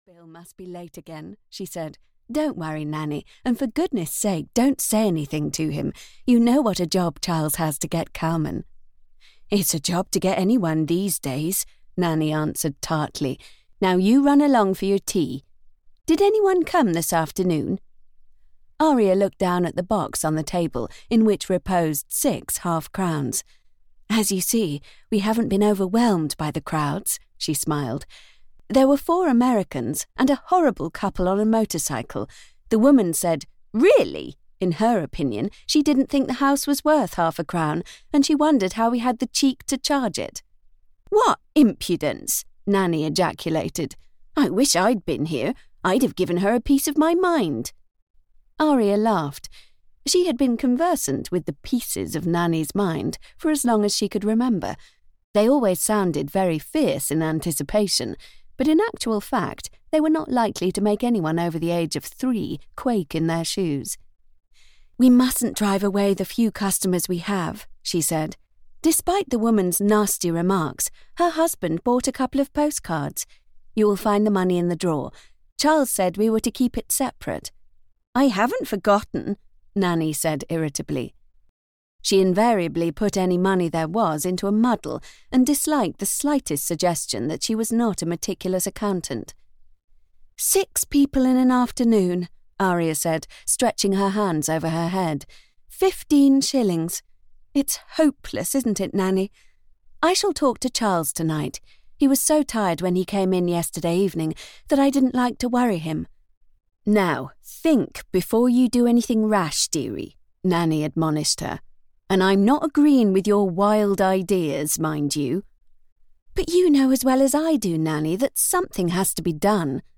Love Forbidden (EN) audiokniha
Ukázka z knihy